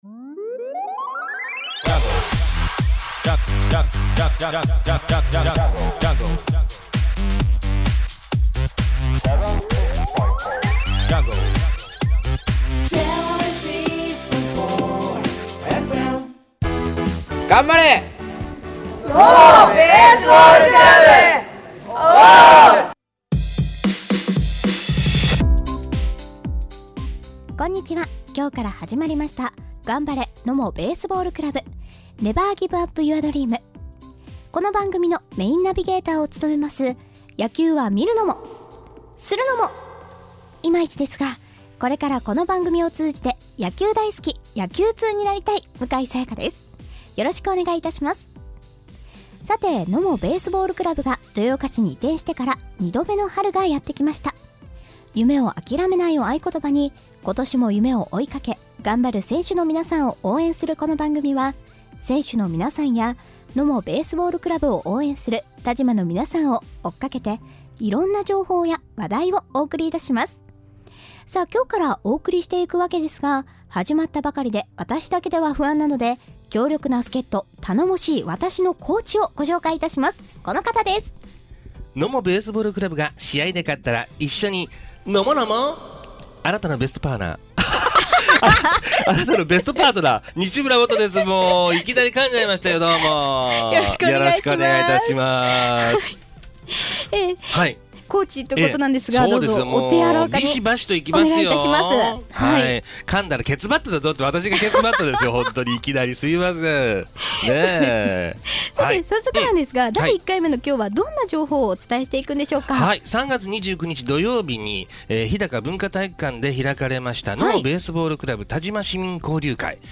放送日：第1回 4/2（水） 3月29日土曜日 日高文化体育館で開かれたNOMOベースボールクラブ但馬市民交流会のもようから一部お送りします。